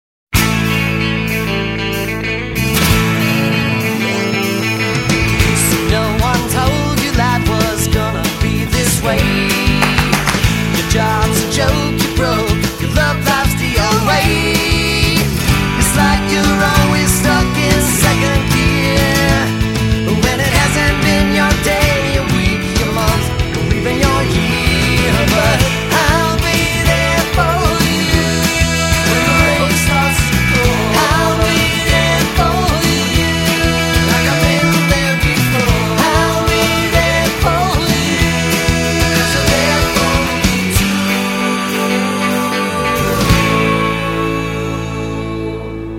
( TV Version 16bits 44 kHz stéréo 725 ko MPEG 3)